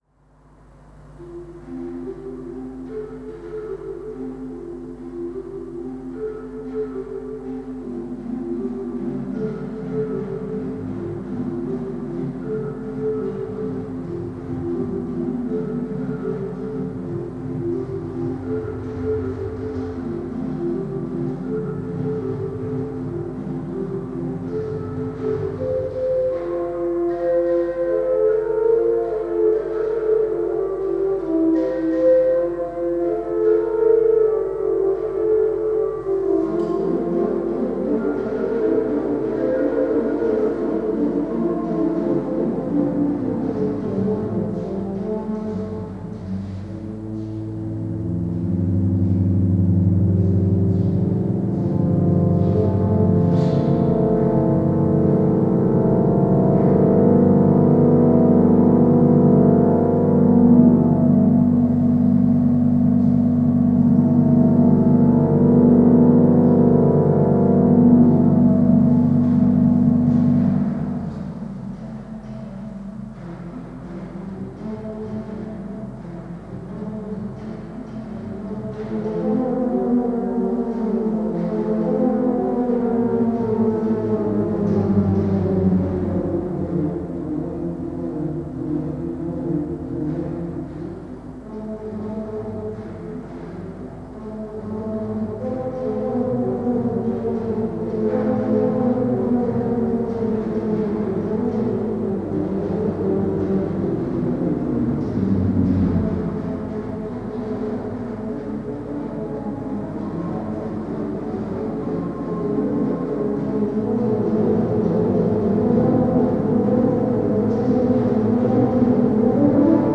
Improvisations sur des images de la Nativité Concert donné en l’église Saint-André de l’Europe (Paris 8), le 4 décembre 2014 Programme intégral disponible ici